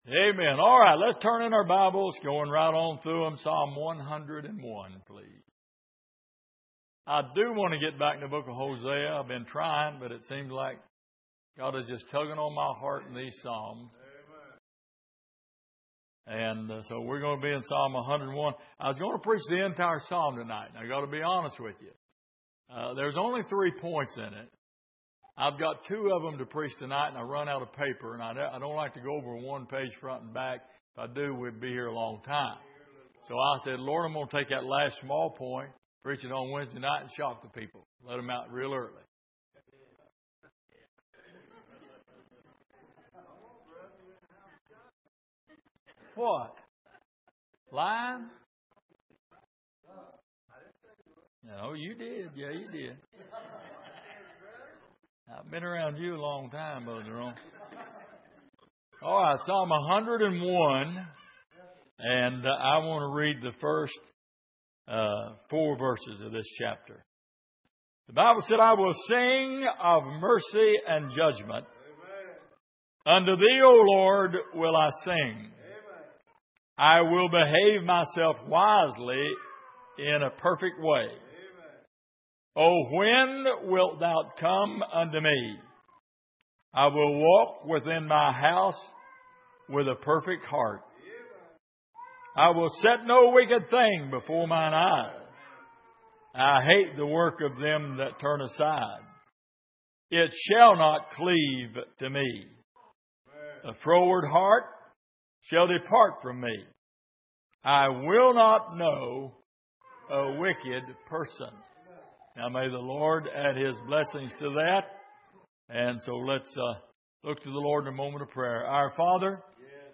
Passage: Psalm 101:1-4 Service: Sunday Evening